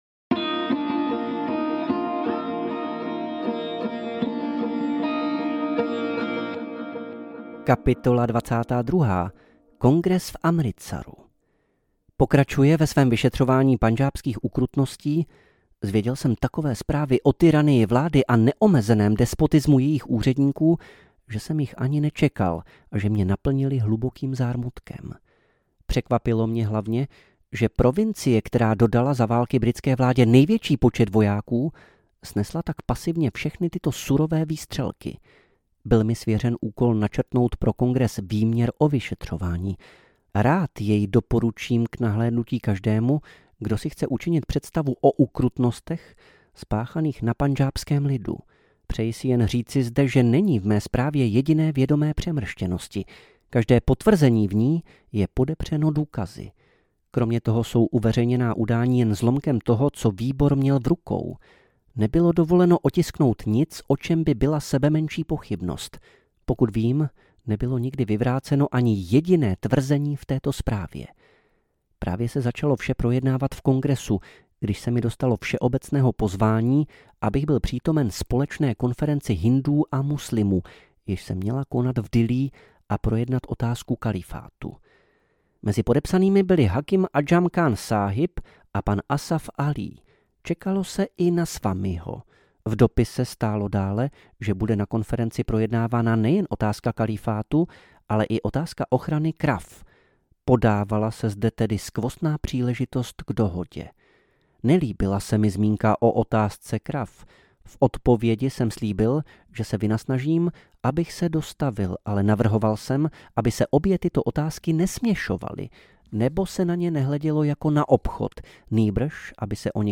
Můj život audiokniha
Ukázka z knihy